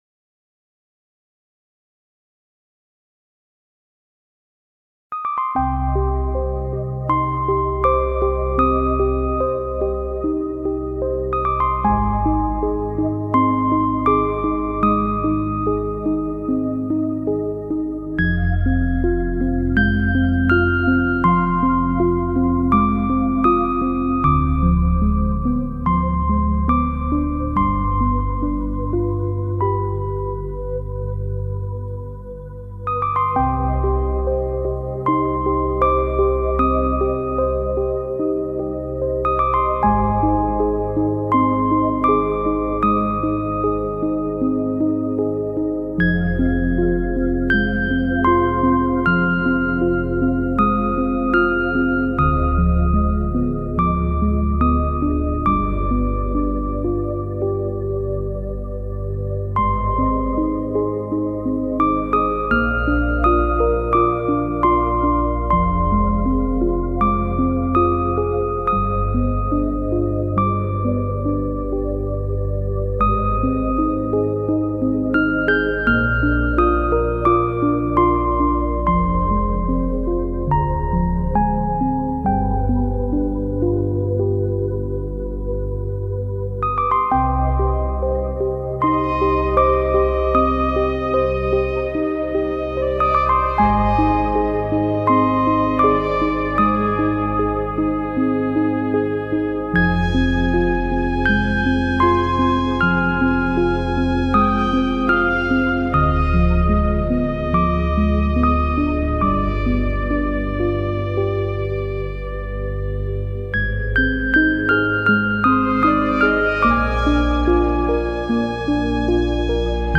piano_-_Волшебная_композиция___Хрустальная_грустьпродолжение_playmus_cc